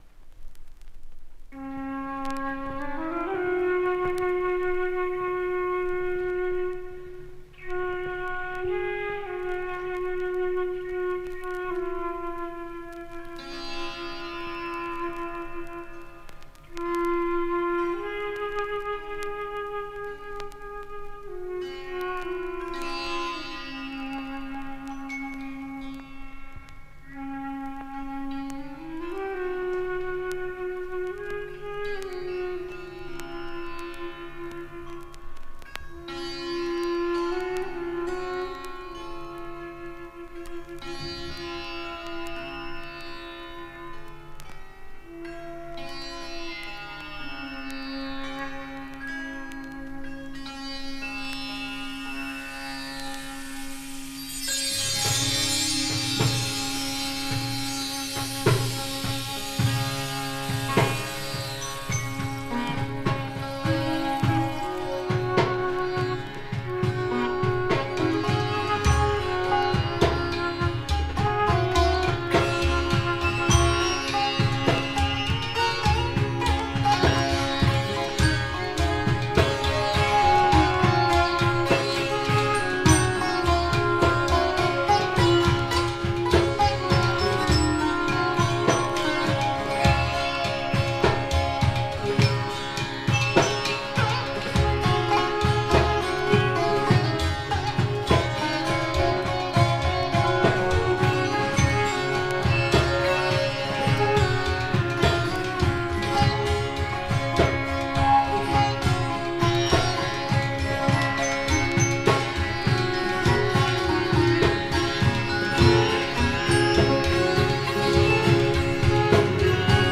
エンテオゲンの効果を聖体物質として再現することを目的としたモンスター・サイケデリック・トリップ。